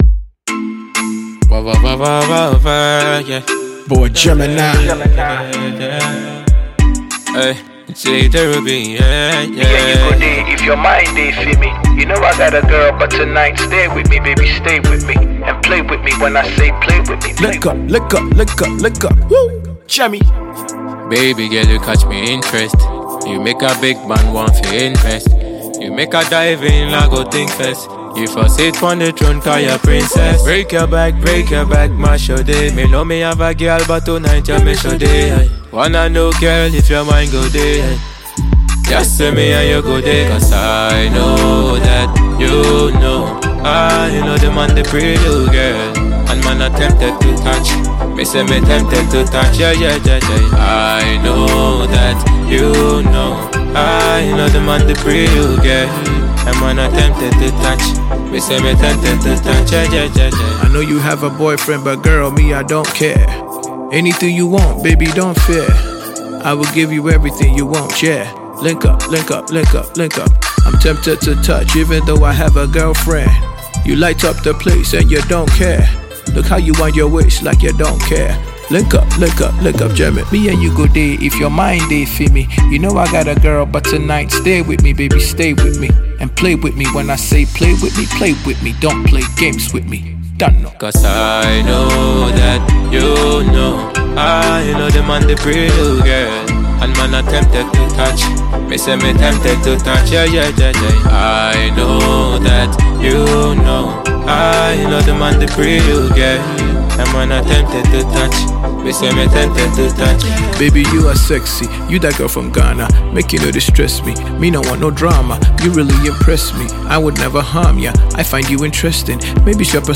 low-tempo joint
Dancehall